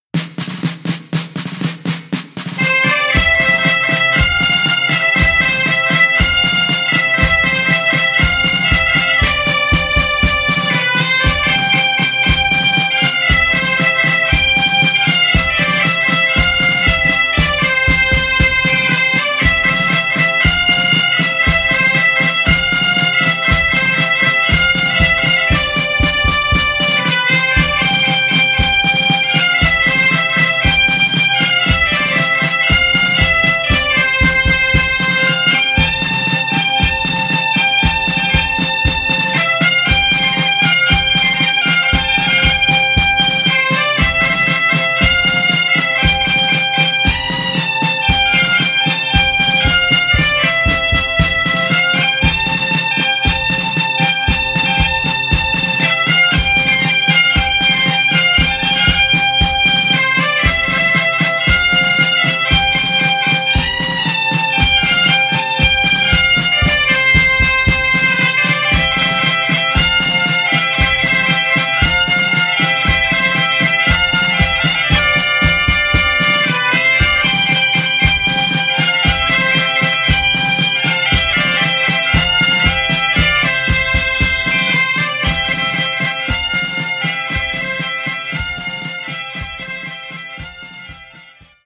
Música tradicional